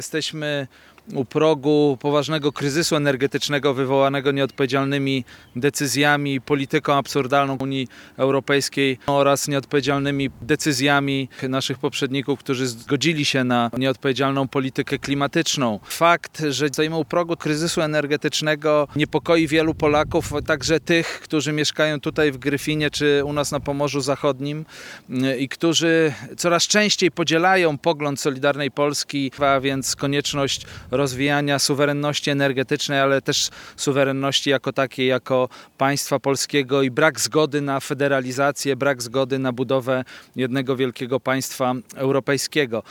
Wicewojewoda Mateusz Wagemann, uczestniczący w spotkaniach i konferencji prasowej, podkreślał kwestie związane z kryzysem energetycznym i brakiem zgody Solidarnej Polski na federalizację Europy.